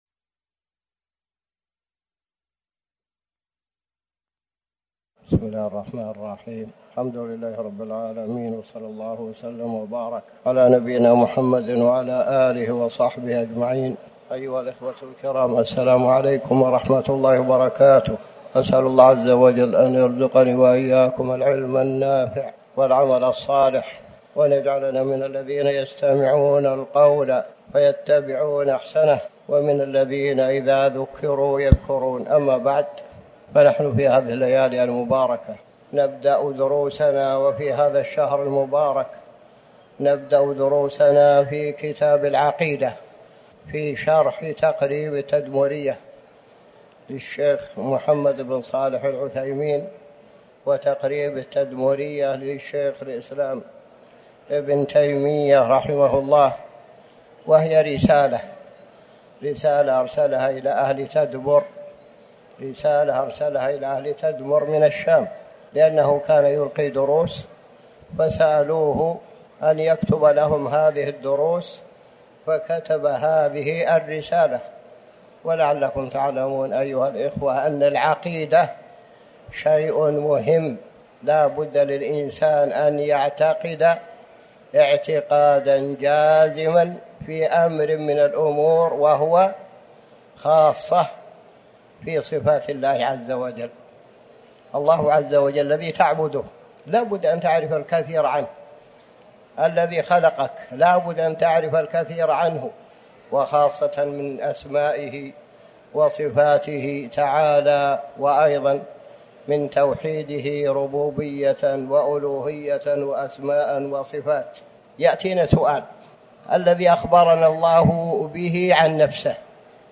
تاريخ النشر ١ ربيع الثاني ١٤٤٠ هـ المكان: المسجد الحرام الشيخ